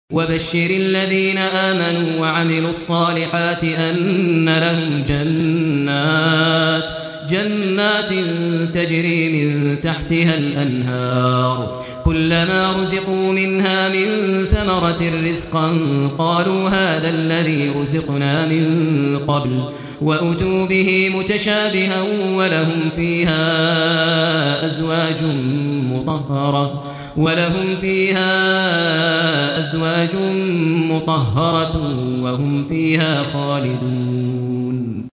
quran-data / data /audio /Qari /Maher_AlMuaiqly /002025_Maher_AlMuaiqly_64kbps.wav